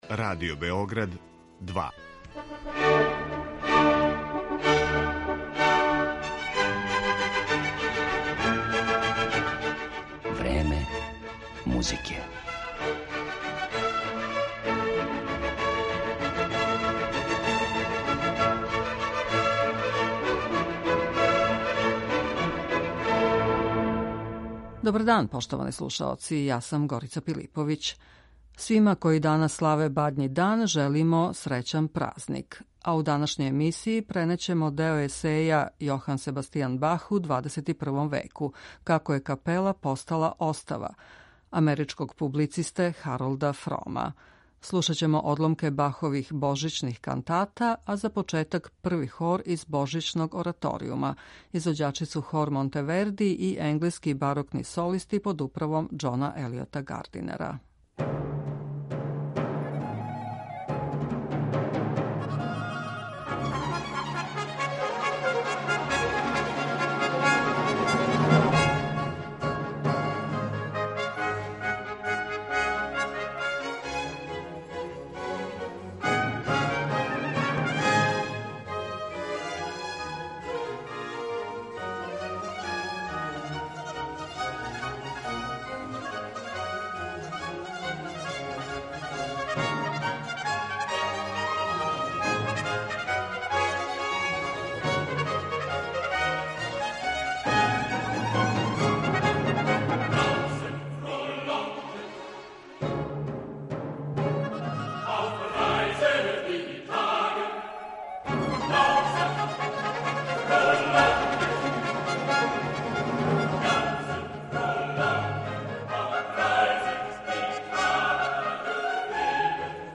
Музички део емисије испуниће одломци Баховог Божићног ораторијума и одговарајућих кантата.